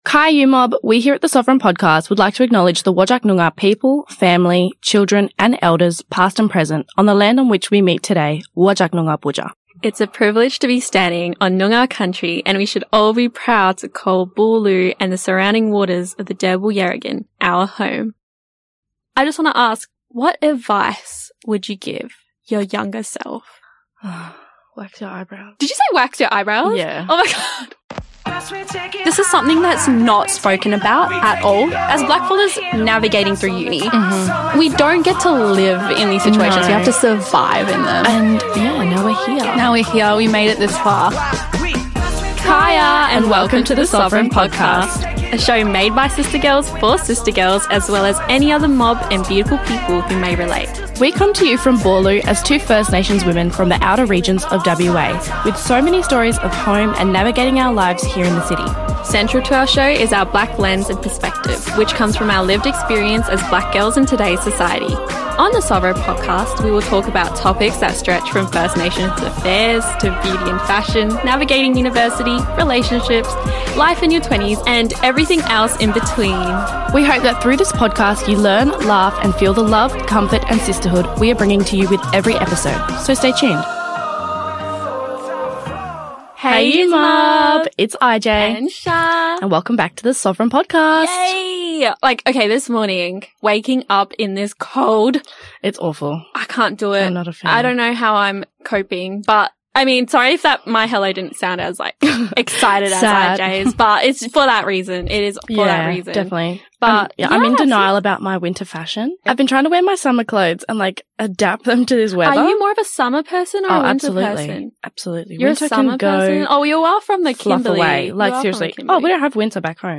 Part two of a two-part conversation about navigating a career and love in your 20s.